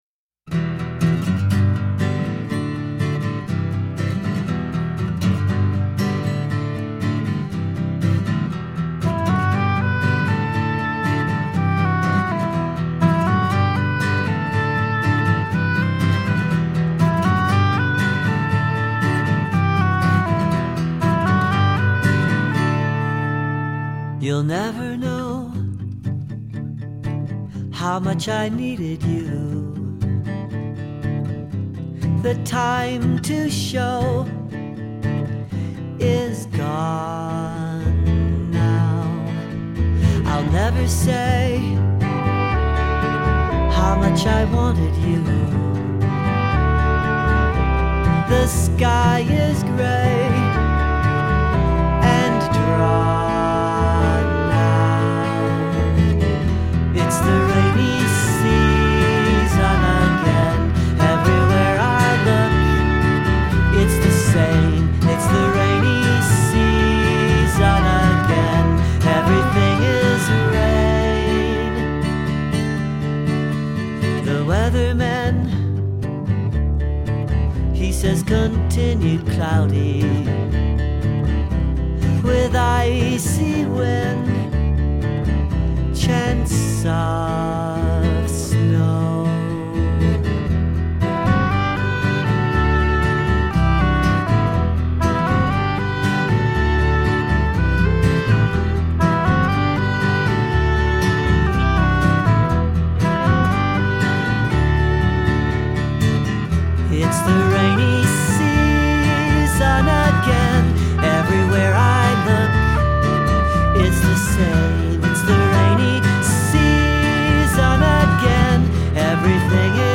chamber-folk